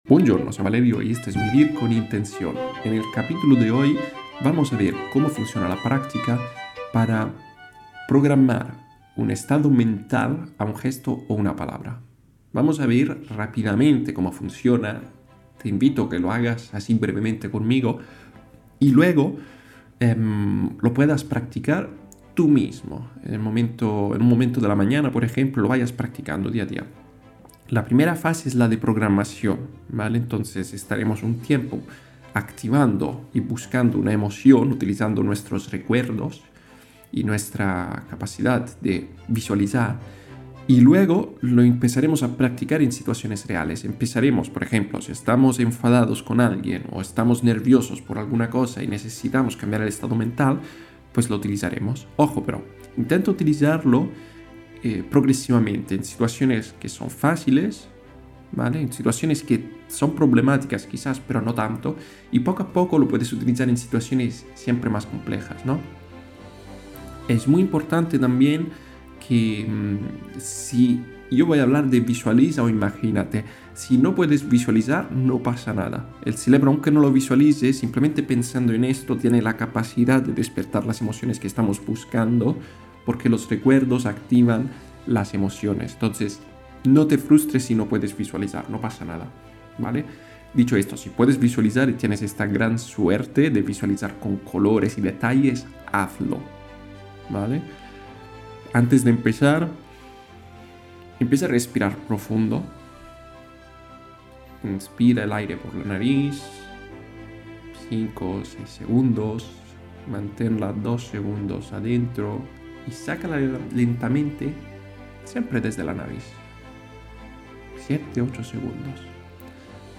Un ensayo mental guiado donde utilizarás visualización, atención y lenguaje interno para prepararte antes de un momento importante.